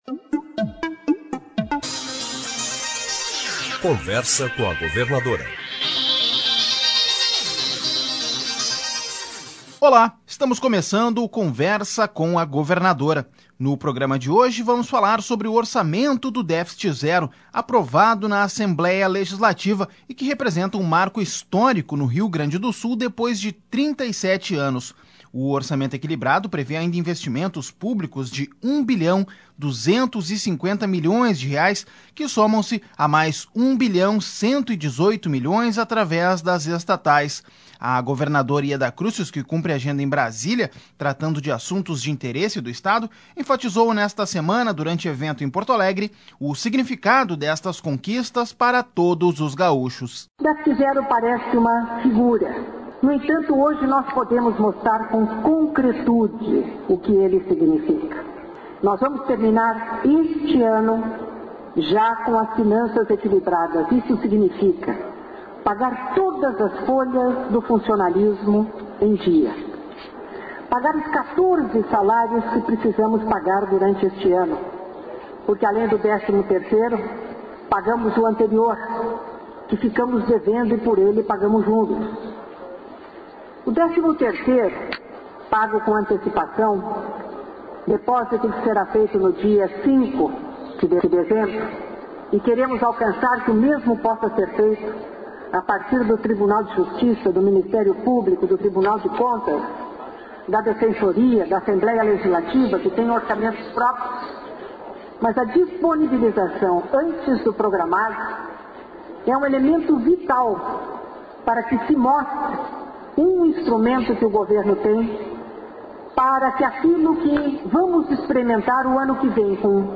No programa Conversa com a Governadora, Yeda Crusius enfatiza a importância do ajuste financeiro no Estado.